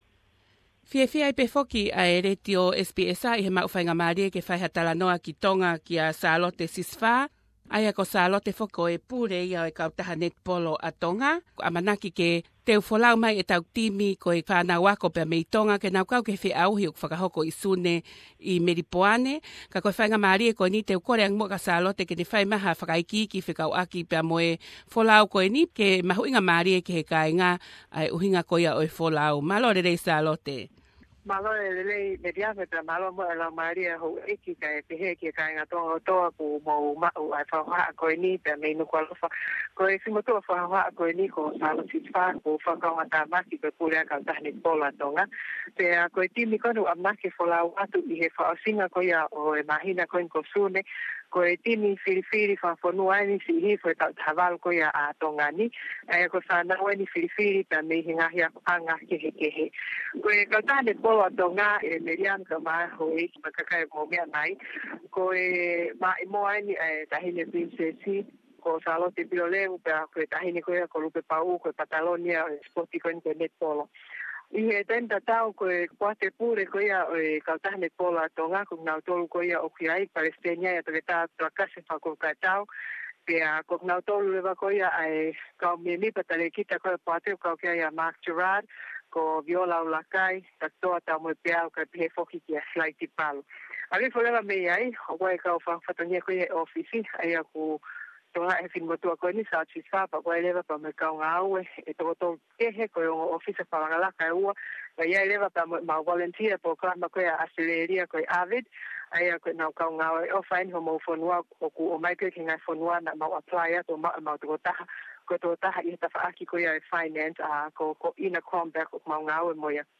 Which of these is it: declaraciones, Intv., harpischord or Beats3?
Intv.